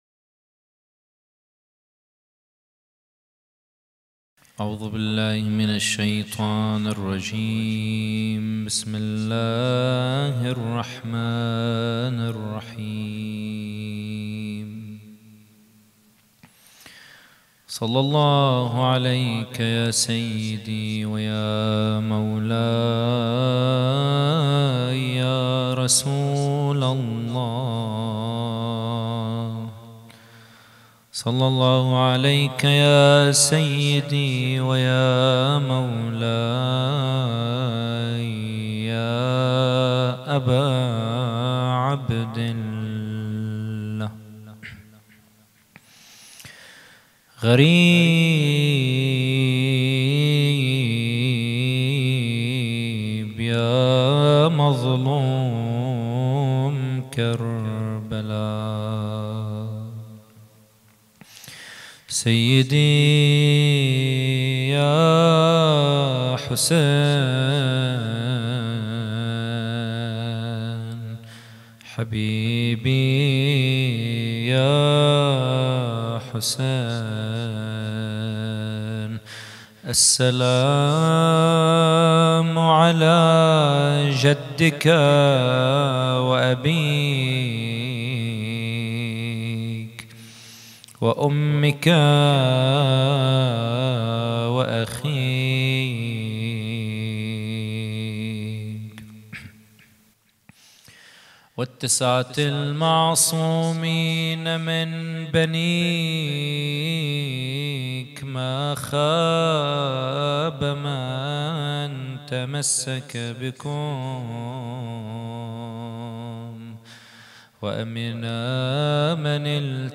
محاضرة
إحياء رابع ليلة من محرم 1442 ه.ق